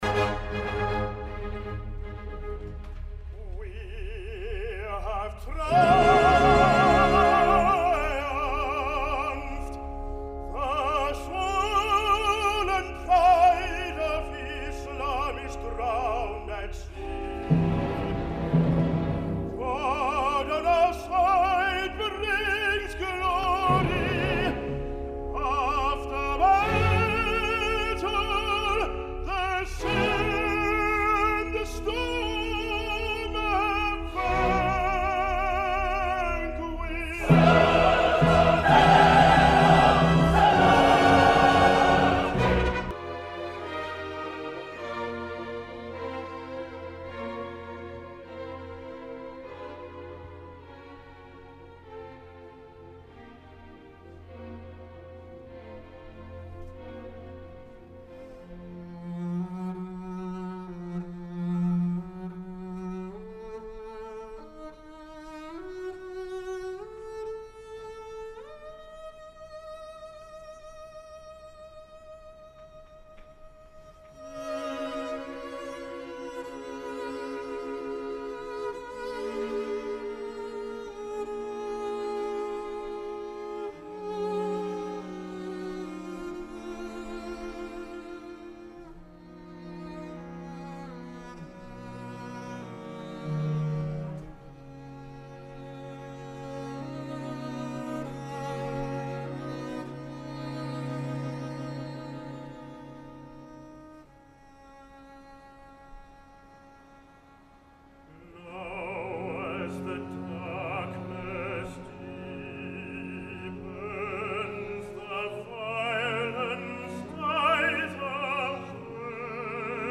Stuart Skelton va néixer l’any 1968 a Sidney, per tant no estem davant d’un tenor jove.